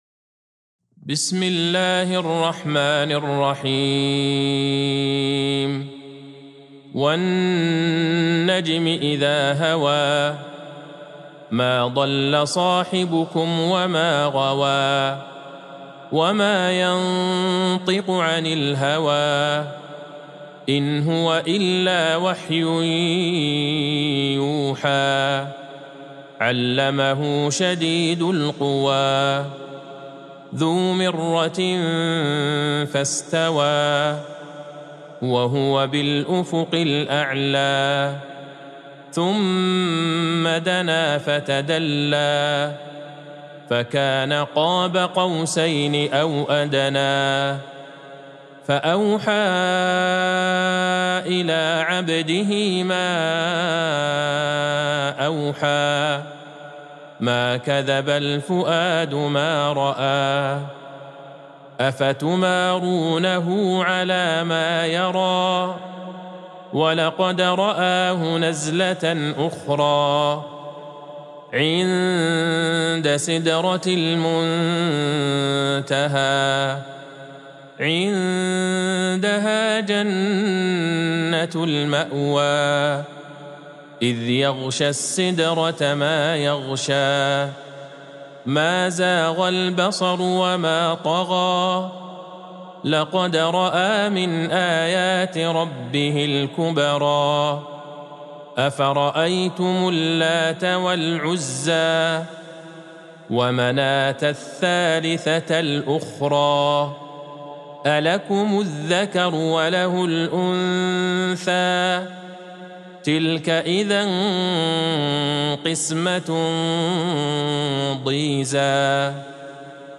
سورة النجم Surat An-Najm | مصحف المقارئ القرآنية > الختمة المرتلة